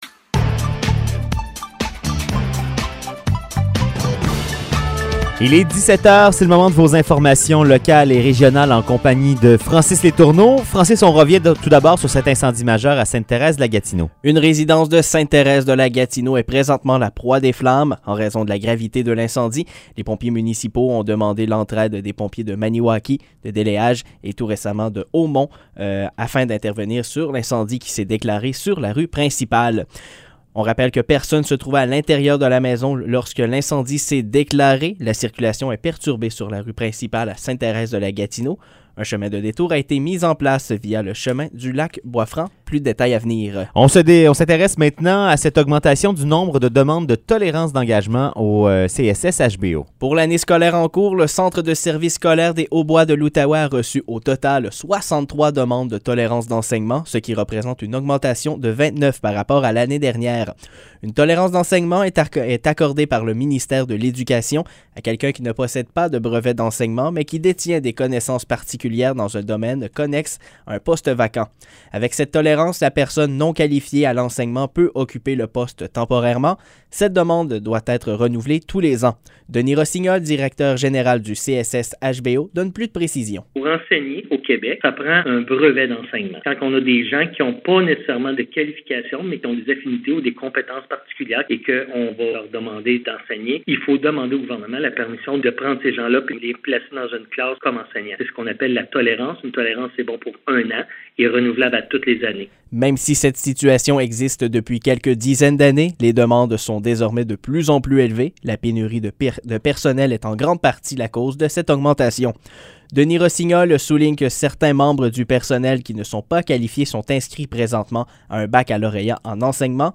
Nouvelles locales - 20 octobre 2021 - 17 h